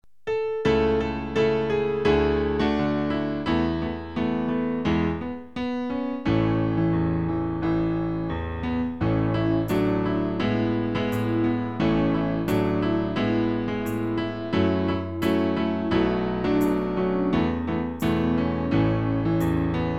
Klavier-Playback zur Begleitung der Gemeinde
(ohne Gesang)